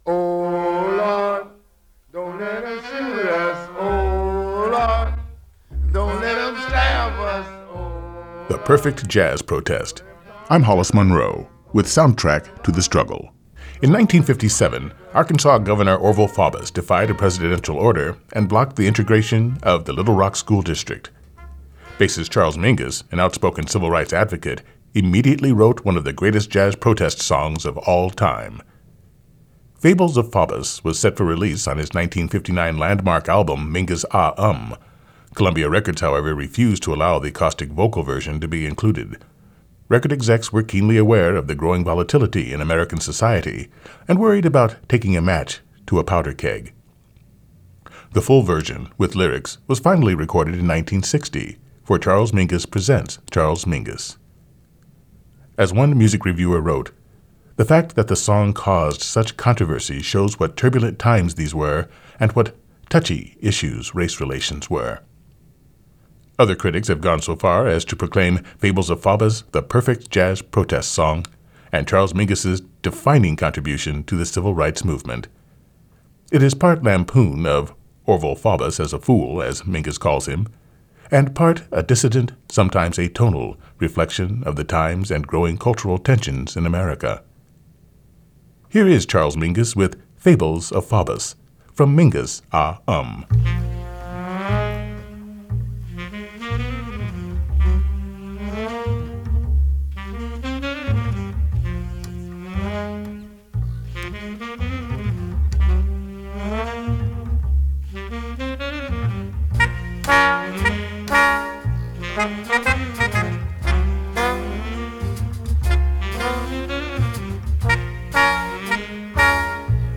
Bassist
The full version, with lyrics, was finally recorded in 1960
and part a dissonant, sometimes atonal